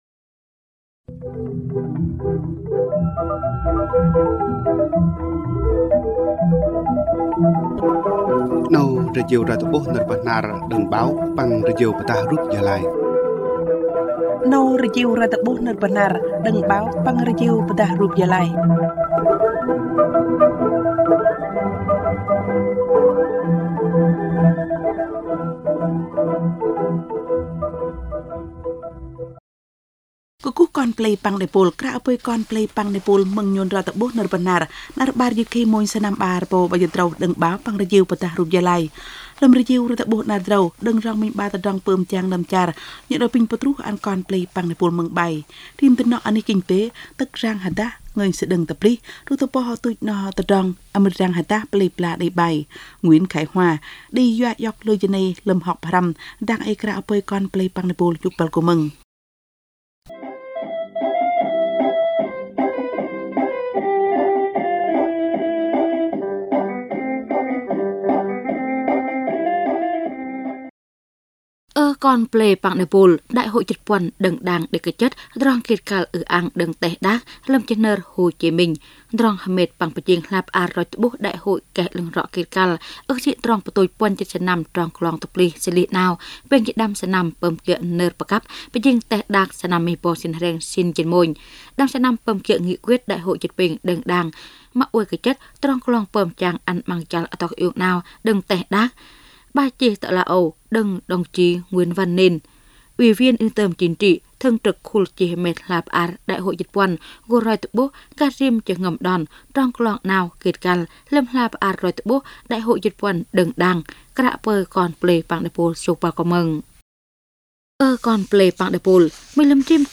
Thời sự PT tiếng Bahnar